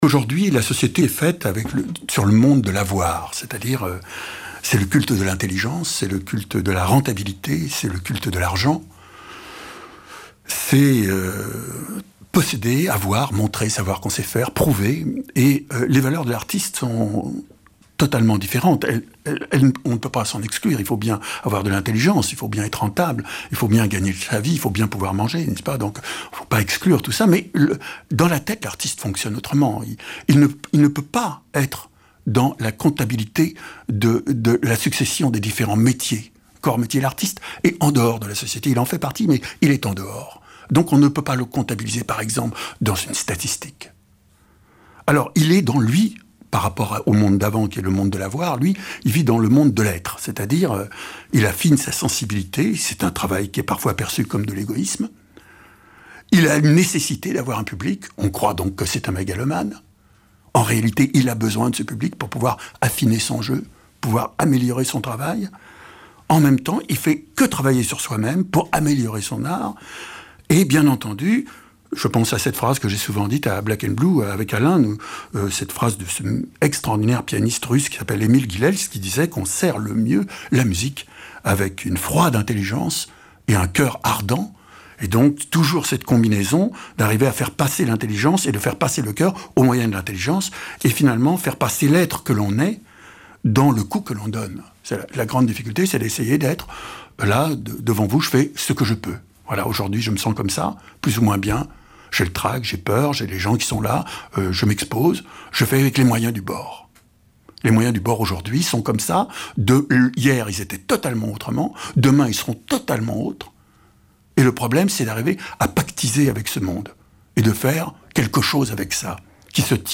Extraits de l’émission Black & Blue d’Alain Gerber sur France Culture